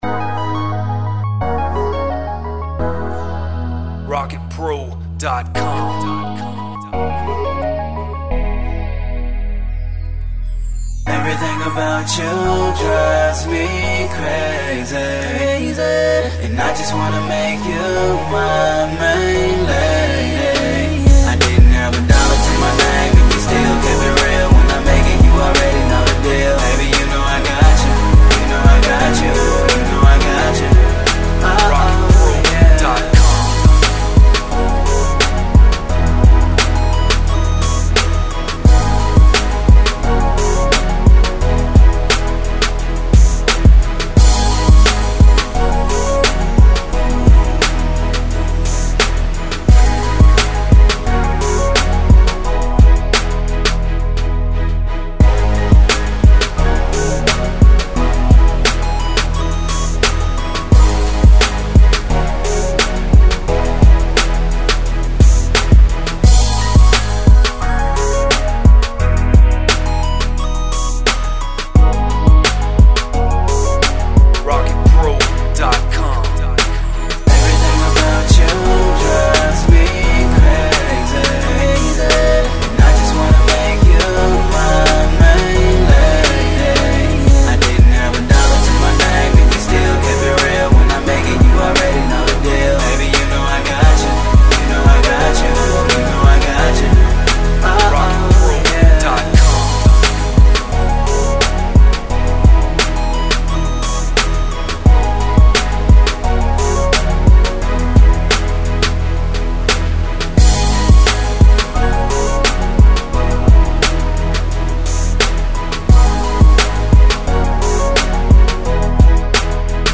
95 BPM.